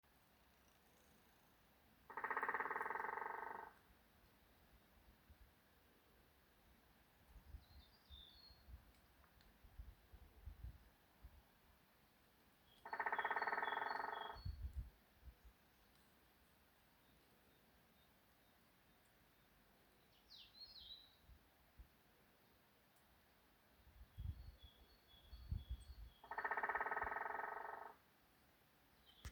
White-backed Woodpecker, Dendrocopos leucotos
StatusSinging male in breeding season